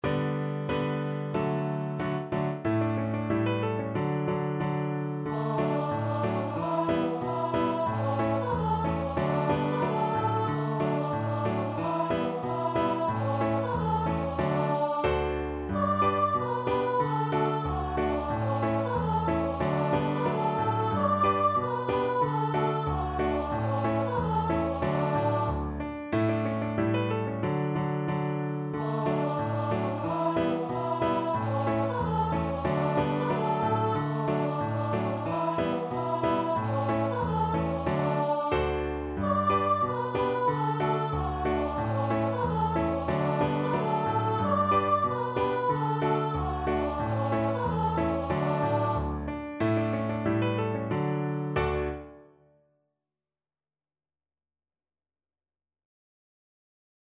Voice
Jolly =c.92
D major (Sounding Pitch) (View more D major Music for Voice )
Swiss